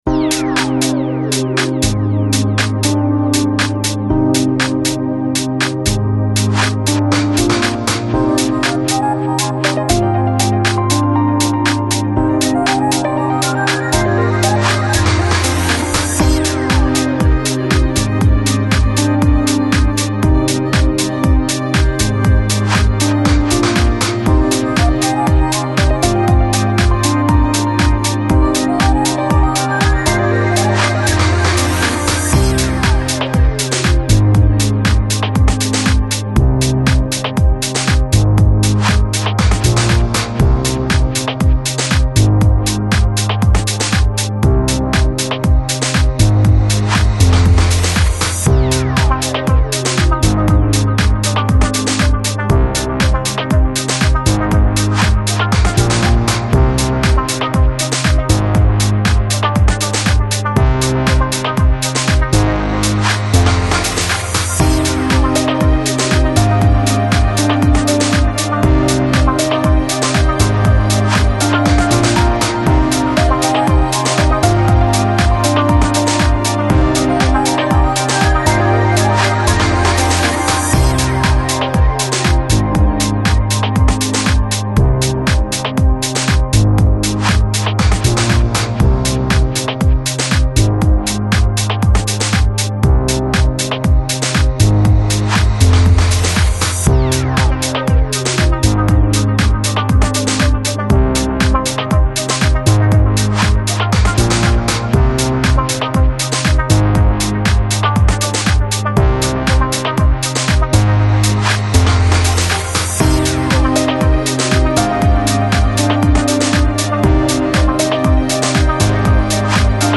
Electronic, Chill House, Balearic, Downtempo Год издания